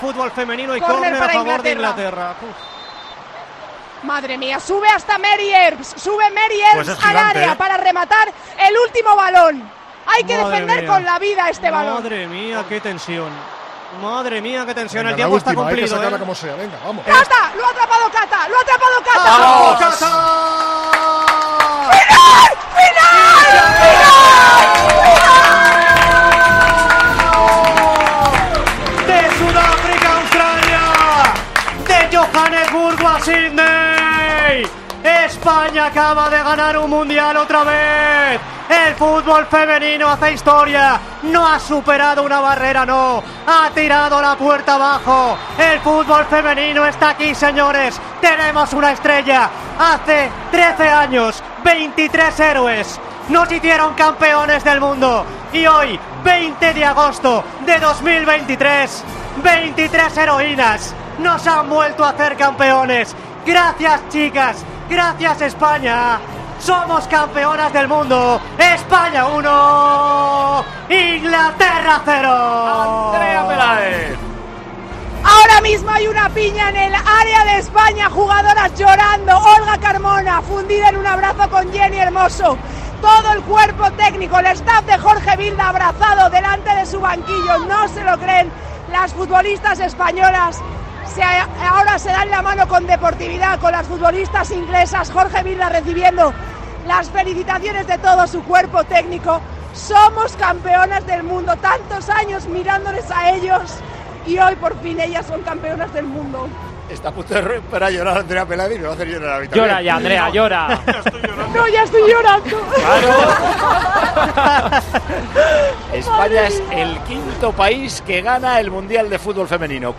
Así narramos el último minuto del Mundial: España es el fútbol mundial